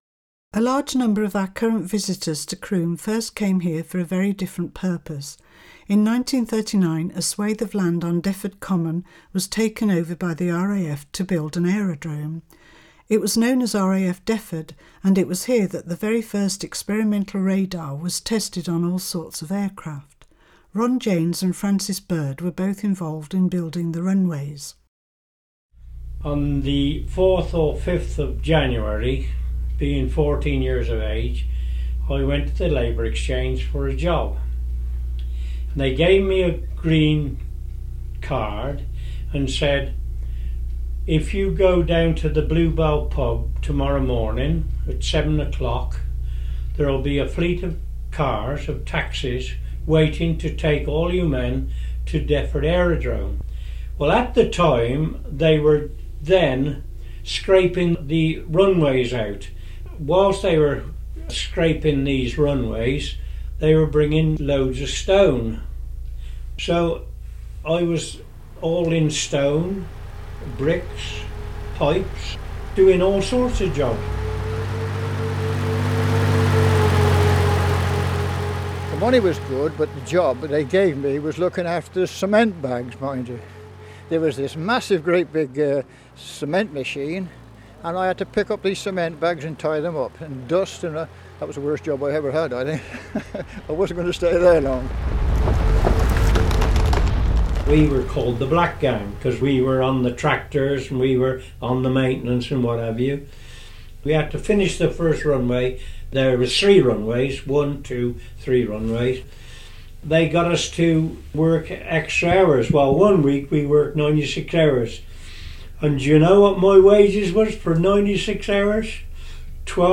Oral History Project...